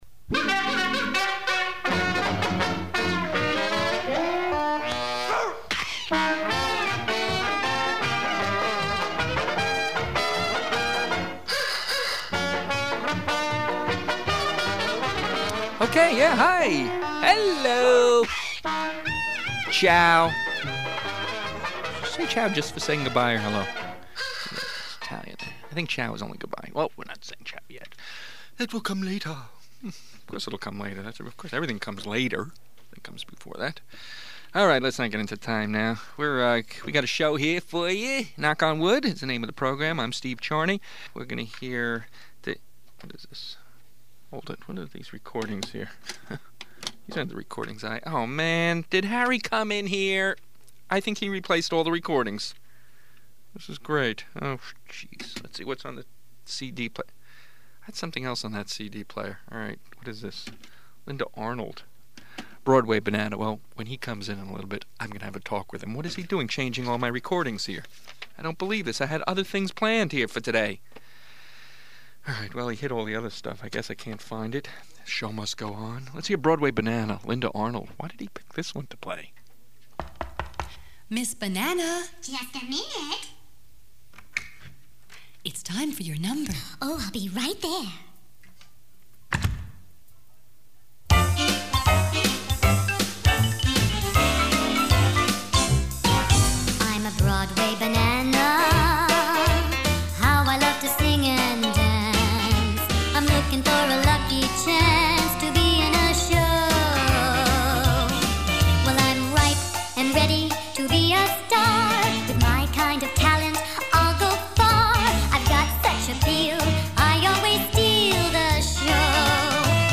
Knock On Wood Comedy Show